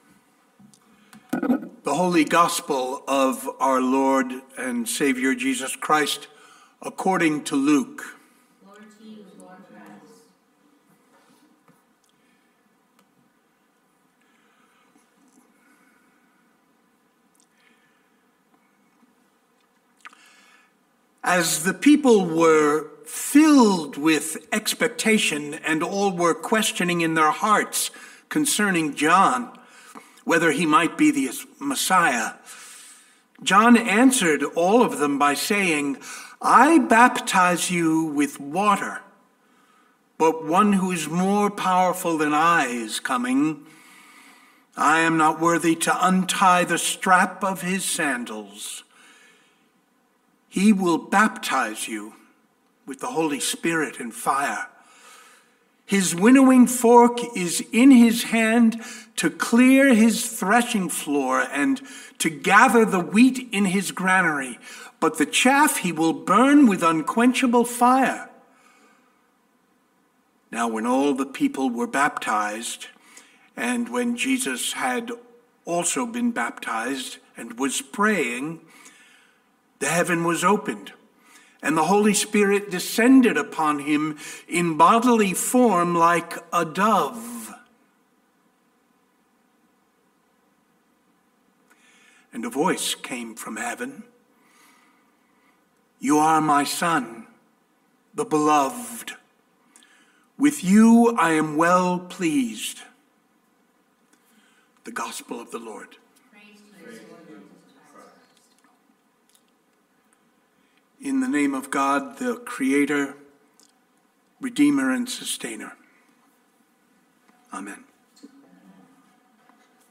Sermons | Bethel Lutheran Church